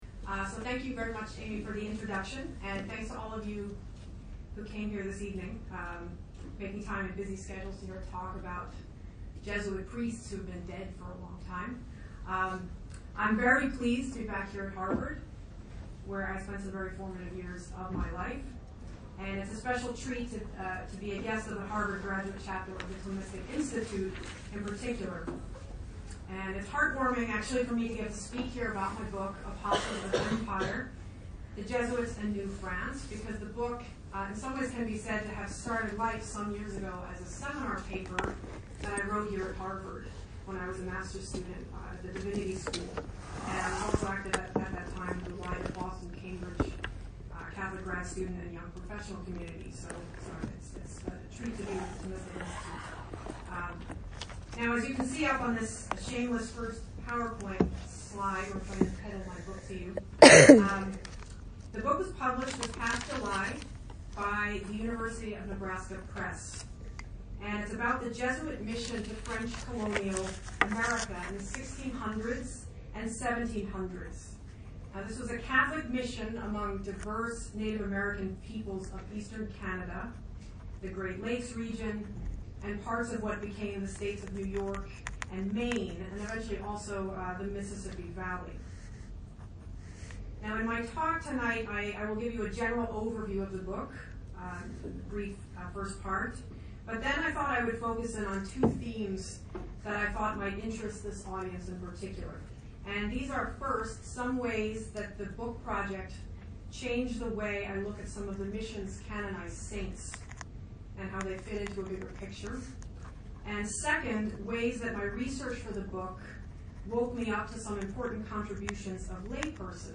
This lecture was offered at Harvard University on 10 October, 2019